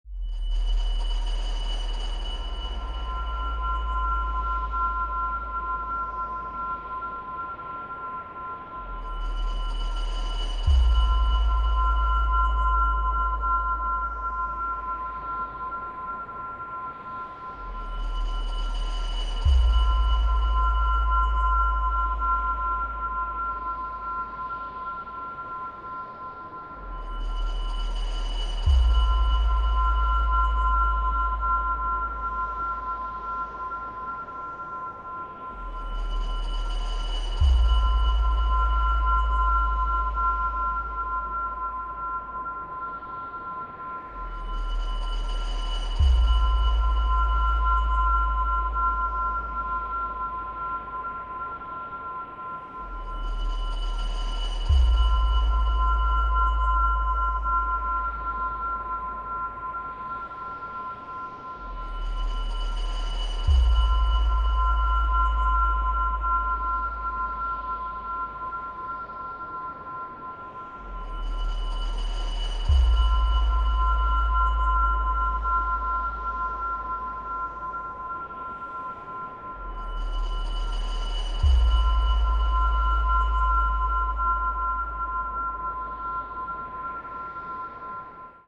This is one of the Bargaining Ambience
Bargaining_Ambient_05.wav